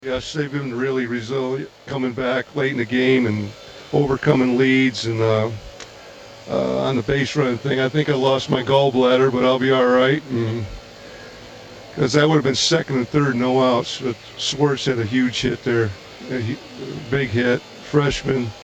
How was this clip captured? Post game sound